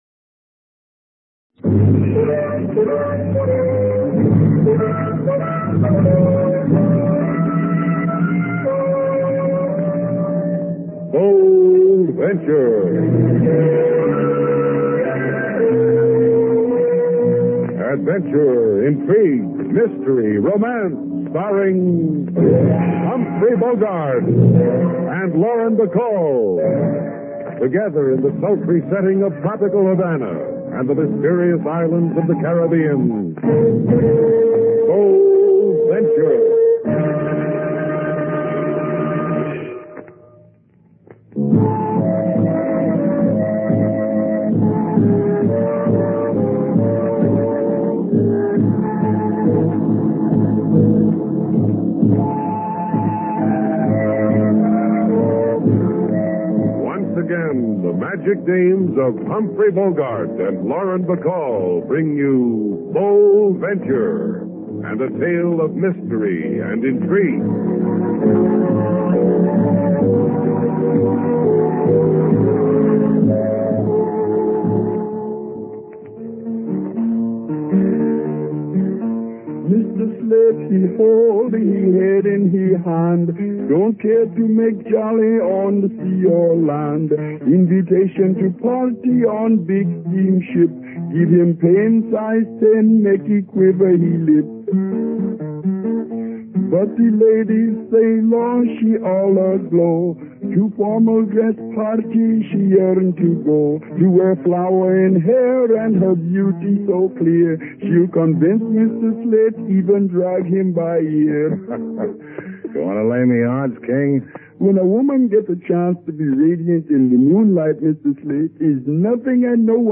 Currently we are offering a old time radio show for your listening pleasure. Bold Venture - Black Tie Affair (Bogart & Bacall) (49-03-20) Please click here to let the adventure begin!